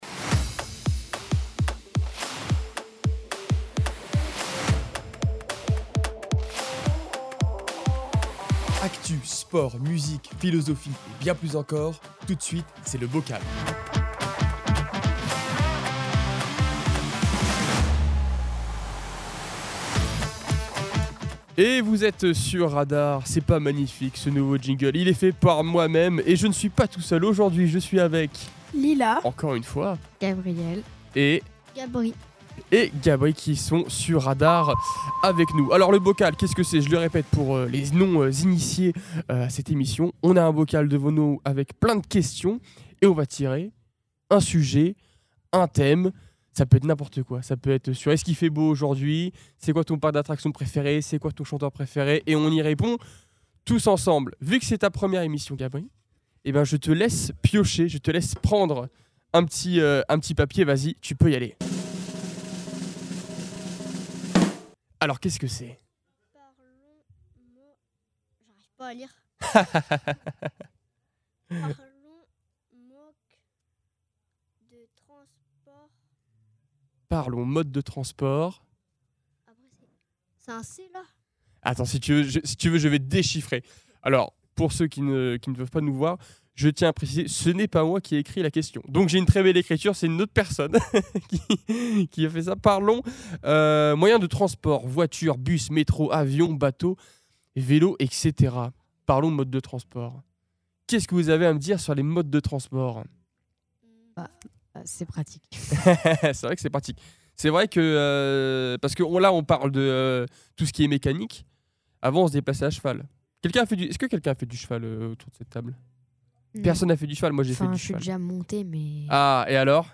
Une poignée d'invités propose des sujets de débats à bulletin secret.
Le sujet, une fois dévoilé, donne lieu à des conversations parfois profondes, parfois légères, toujours dans la bonne humeur !